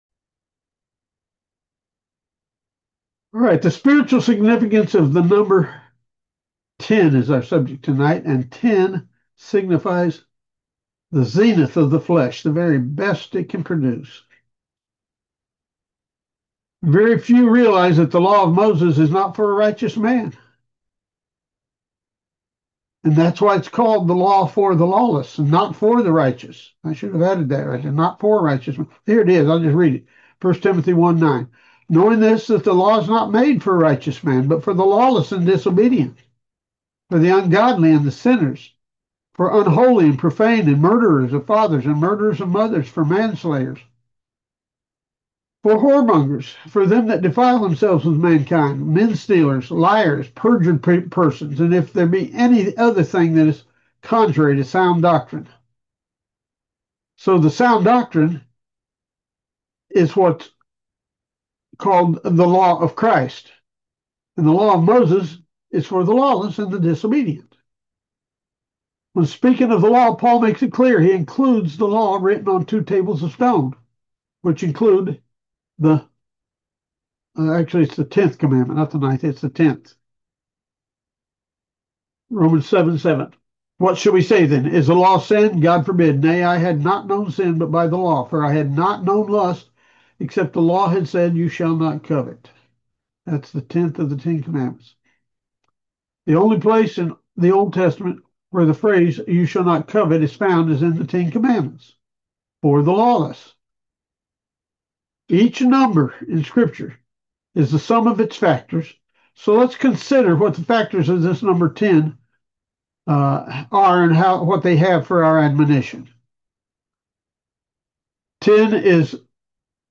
Audio Download Spiritual Significance of Numbers: Ten is the Zenith of the Flesh [Study Aired February 6, 2026] Very few realize that the law of Moses is “not for a righteous man”.